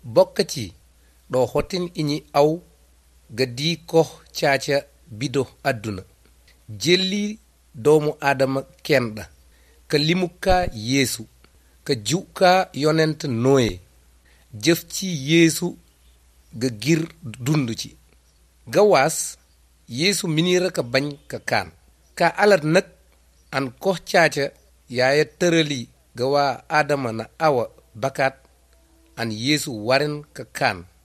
10 June 2018 at 7:41 pm I hear central vowels, dorsal fricatives, and palatal consonants.
Then again, I don’t hear any ejectives.